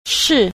c. 式 – shì – thức